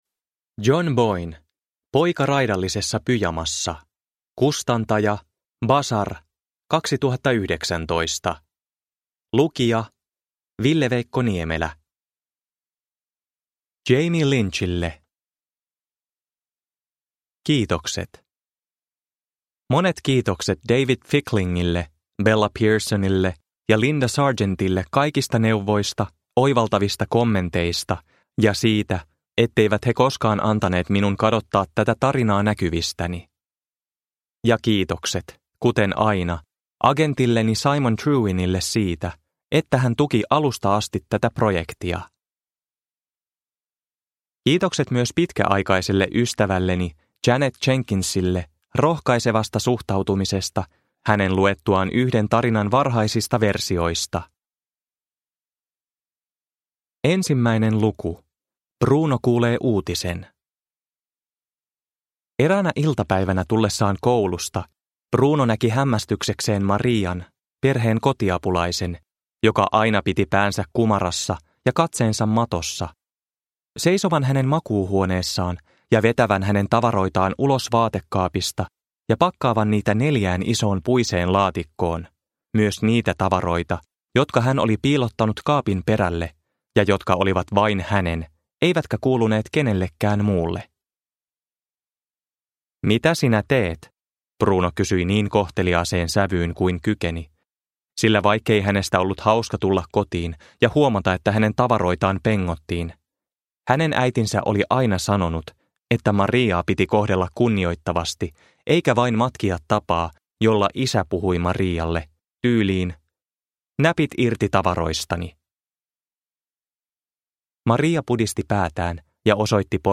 Poika raidallisessa pyjamassa (ljudbok) av John Boyne